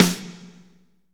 SNR A C S06L.wav